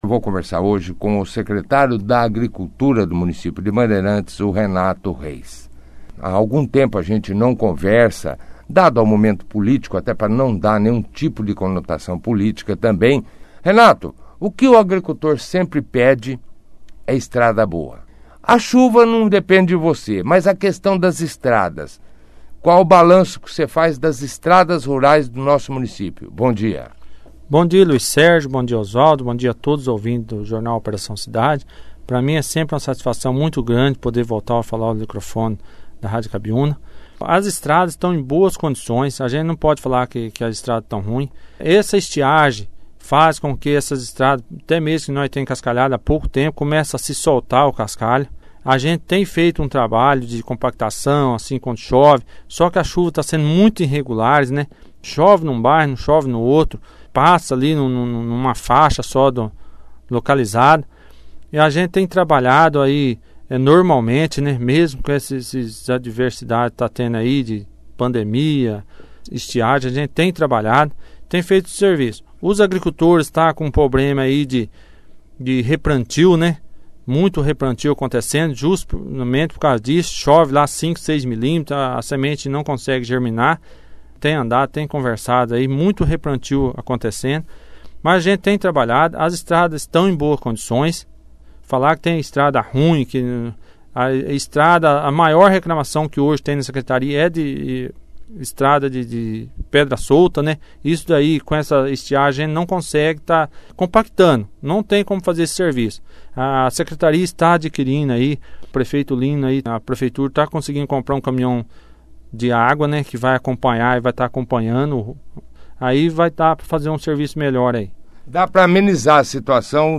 O Secretário da Agricultura e Pecuária de Bandeirantes, Renato Reis, (foto), participou da edição deste sábado, 05/12, do jornal Operação Cidade, onde falou sobre a situação atual das estradas da zona rural, com a chuva que vem caindo ao ao longo dos últimos dias e também fez um balanço de sua atuação durante o tempo que esteve diante da secretaria.